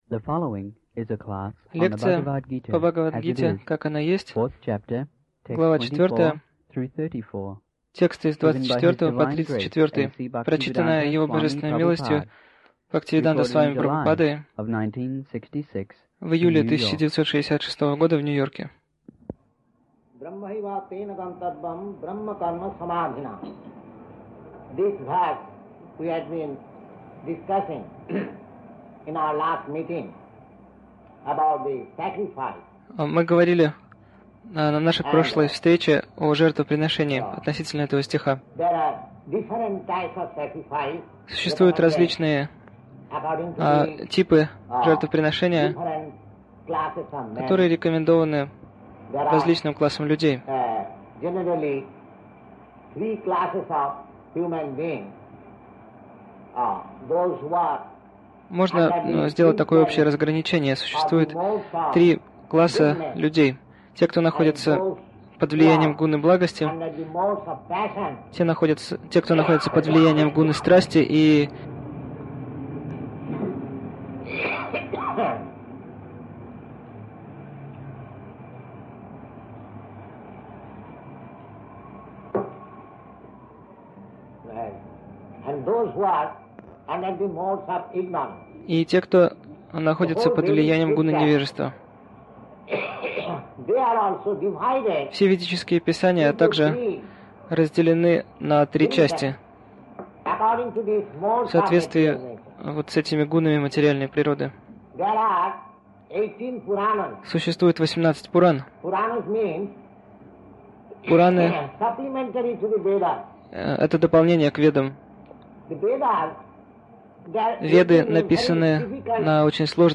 Милость Прабхупады Аудиолекции и книги 12.08.1966 Бхагавад Гита | Нью-Йорк БГ 04.24-34 Загрузка...